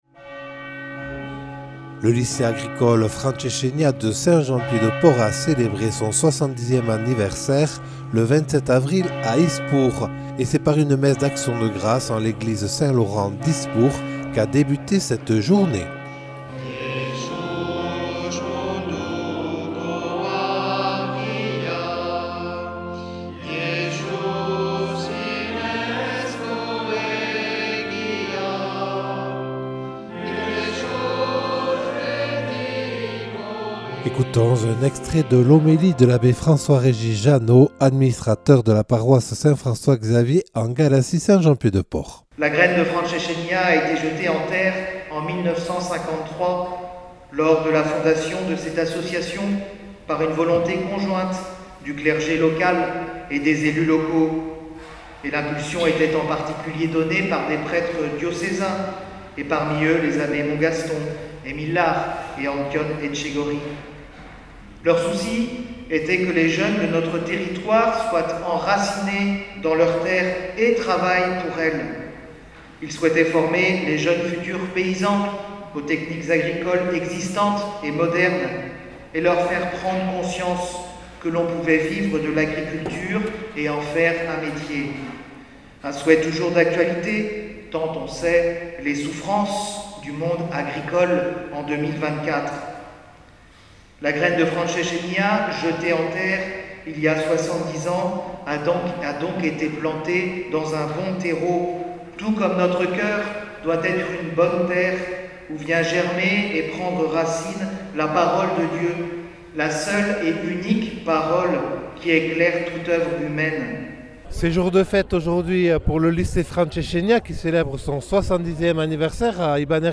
Le lycée agricole Frantsesenia de Saint Jean-Pied de Port a célébré son 70ème anniversaire le 27 avril 2024 à Ispoure : reportage.